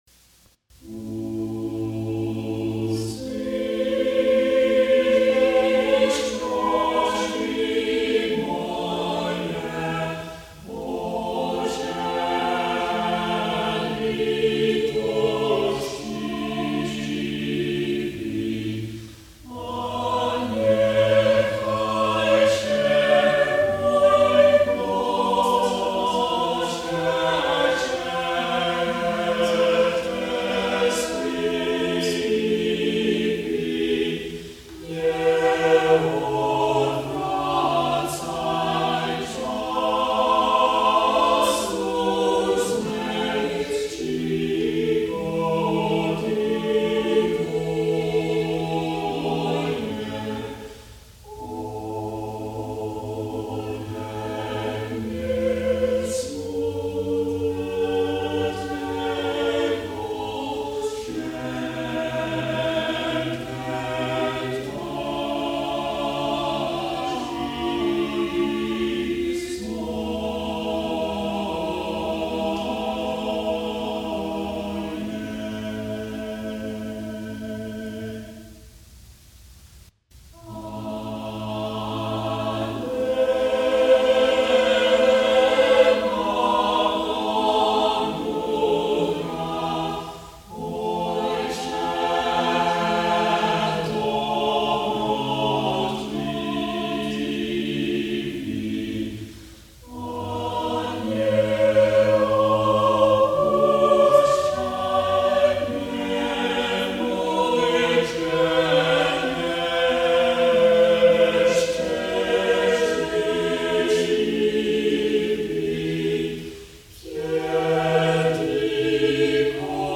| Vocal Ensemble 'Cracow' session 1973